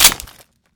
Index of /server/sound/weapons/cw_mr96
chamber_in.wav